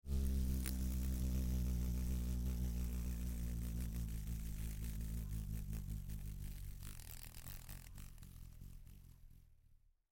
Звуки шершня
Звук где шершень сел на цветок